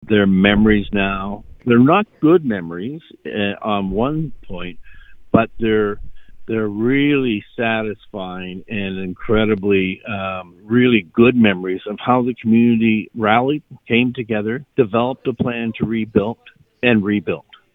Goderich Mayor John Grace was the Deputy Mayor at the time, and says that looking back, even though the memories with the event are mostly bad, there are some good ones.